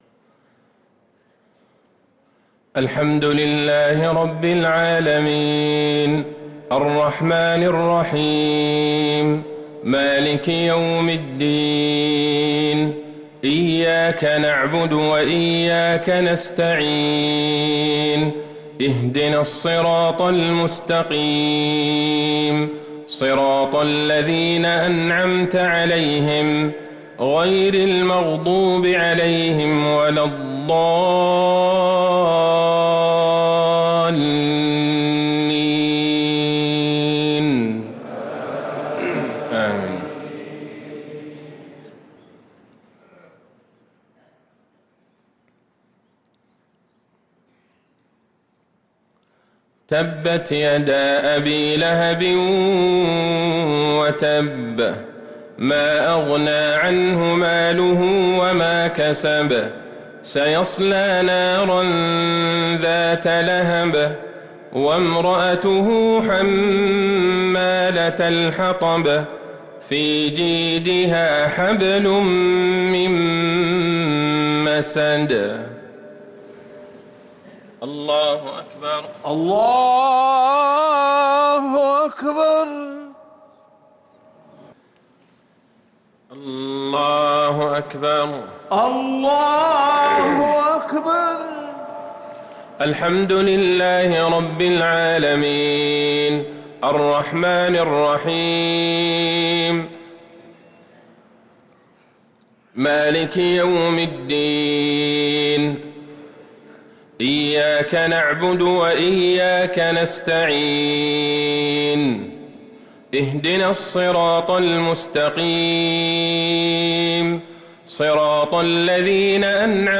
صلاة المغرب للقارئ عبدالله البعيجان 17 شعبان 1444 هـ
تِلَاوَات الْحَرَمَيْن .